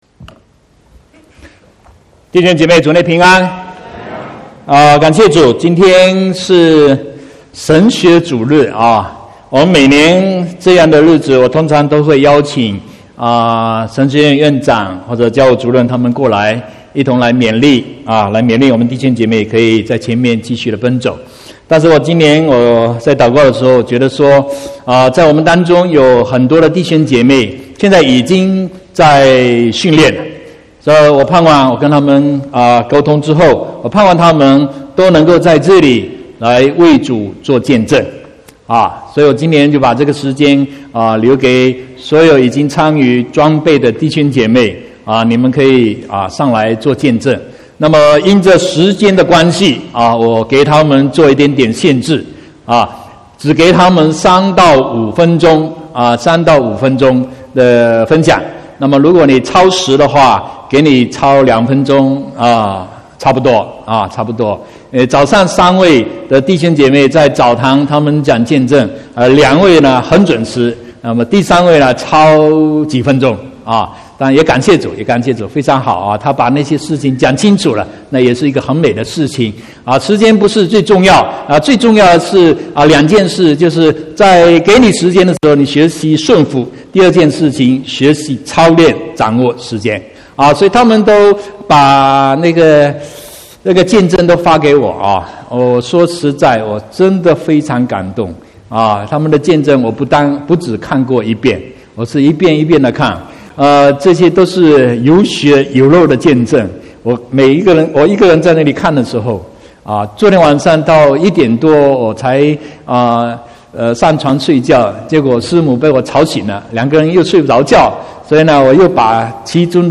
9/6/2019 國語堂講道